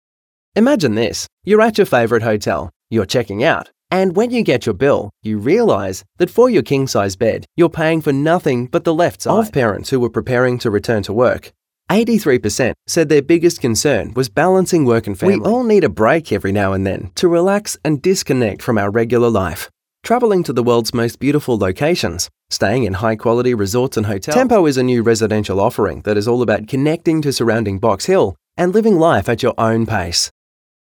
Male
English (Australian)
Yng Adult (18-29), Adult (30-50)
Natural Speak
No Processing - Natural Demo.